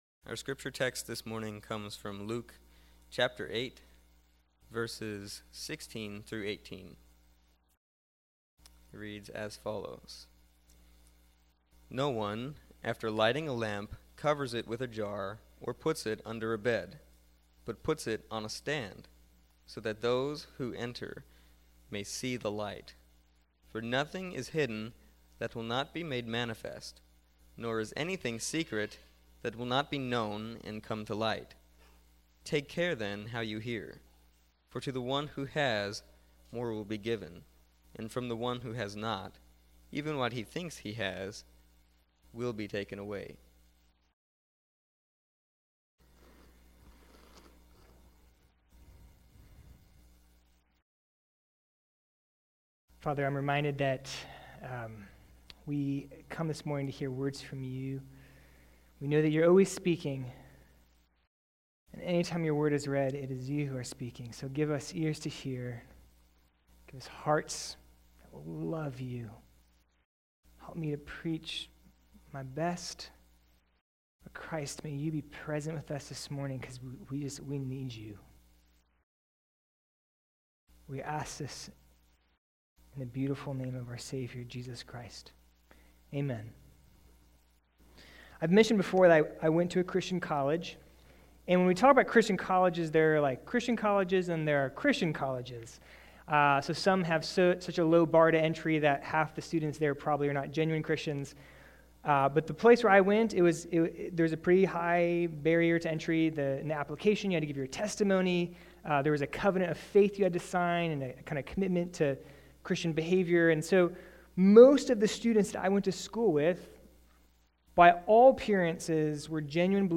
Series The Gospel of Luke Service Morning Worship
Full Service October 4 Sermon Audio Bible References Luke 8:16